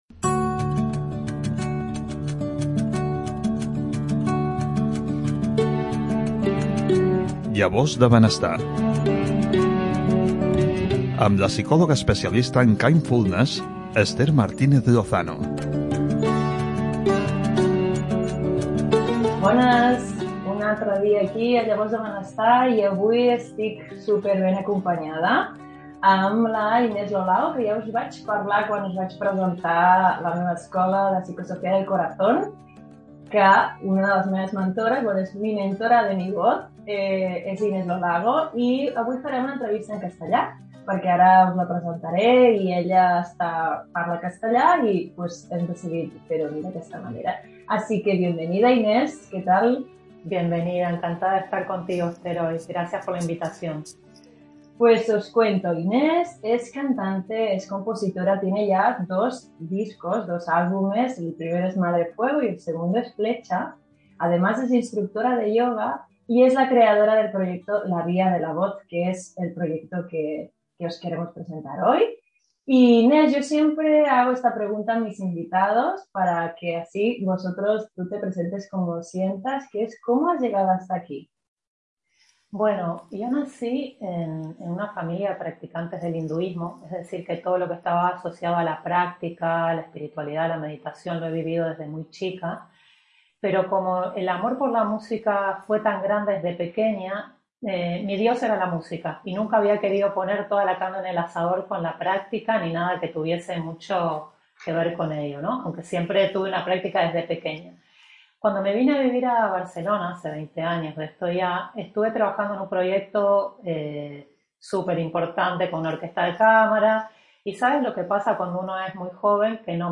Llavors de Benestar - Entrevista